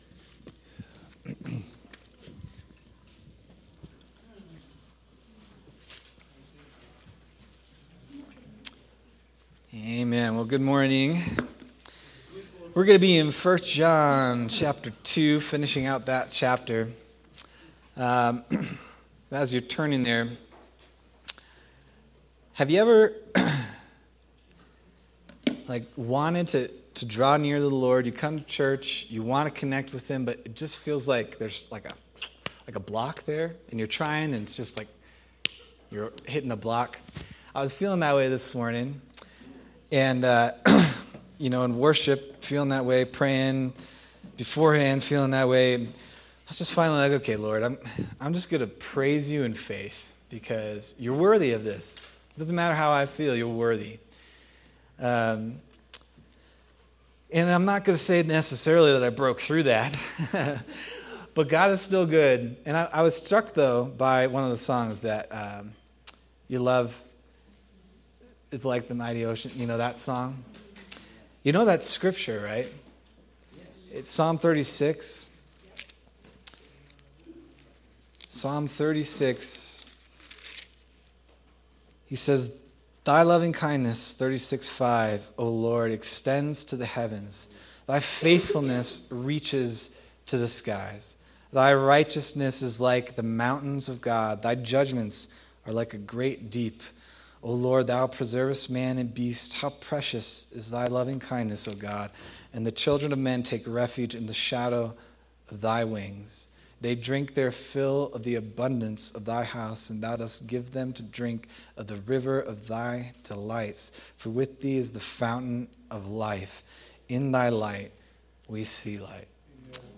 March 1st, 2026 Sermon